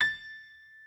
pianoadrib1_32.ogg